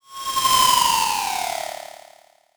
digital effect electro noise sound effect free sound royalty free Sound Effects